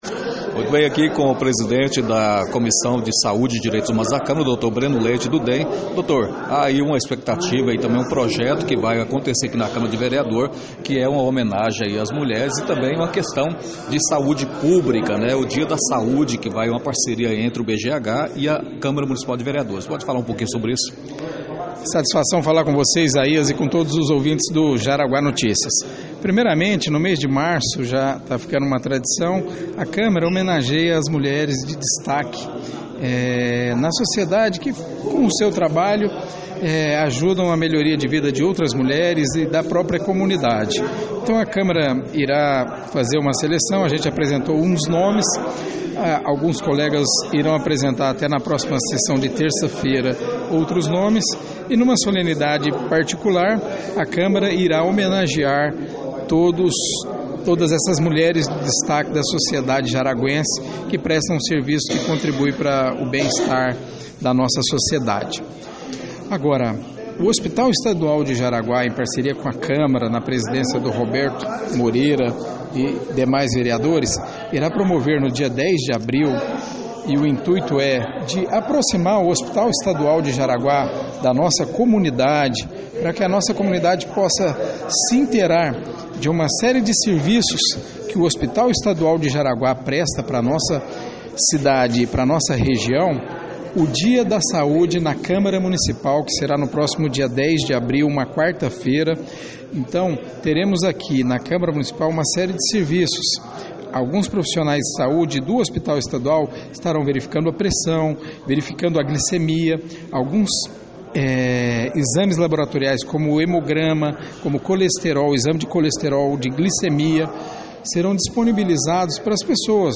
Ouça a entrevista do médico e vereador Breno Leite